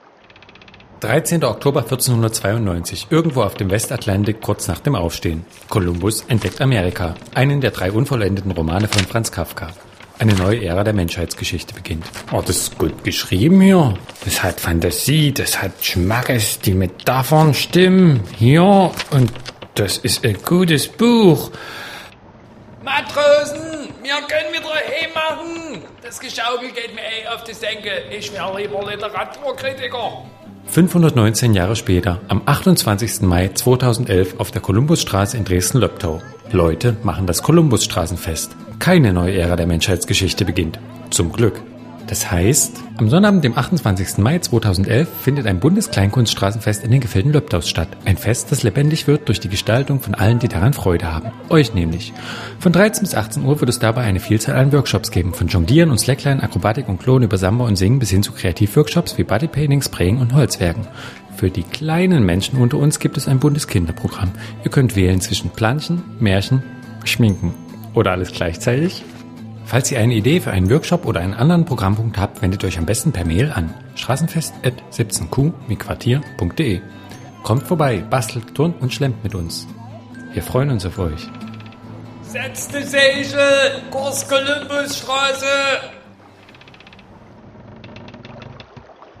Radio Jingle zum Columbusstraßenfest
columbusstrassenjingle.mp3